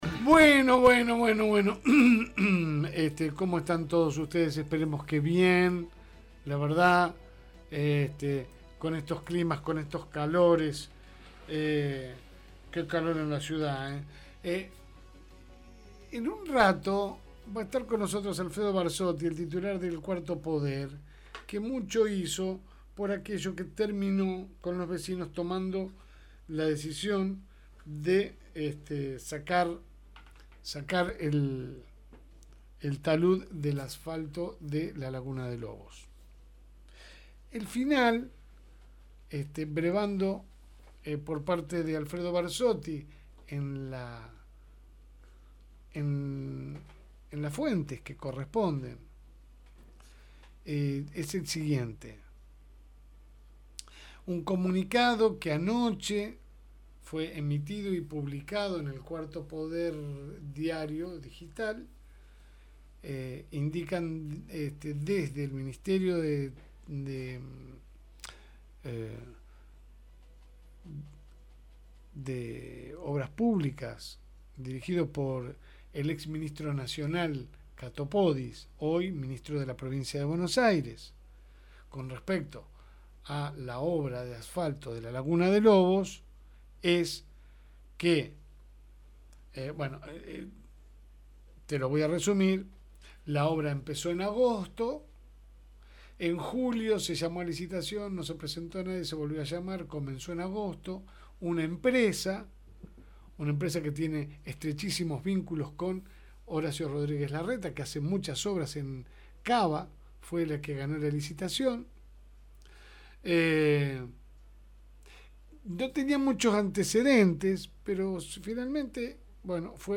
EDITORIAL – AYYY… POBRE PINOCHO – FM Reencuentro
La Segunda Mañana sale por el aire de Fm Reencuentro 102.9 de lunes a viernes de 10 a 12 hs.